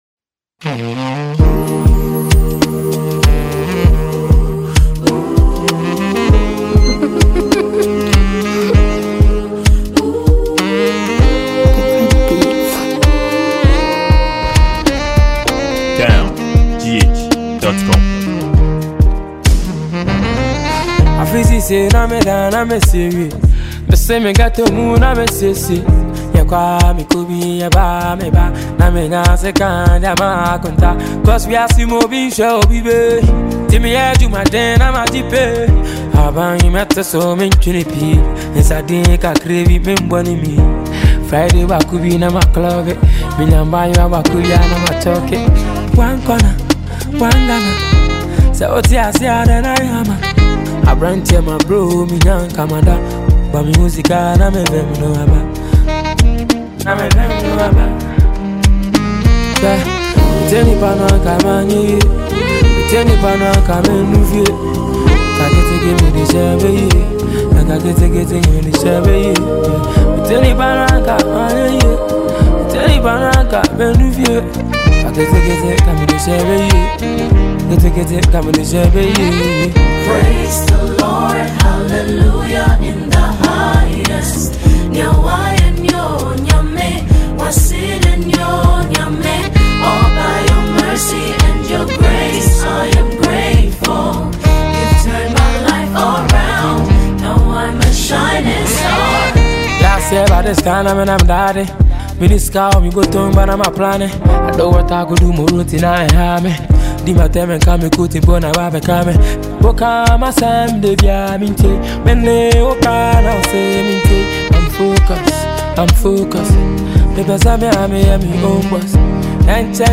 a Ghanaian songwriter and talented highlife singer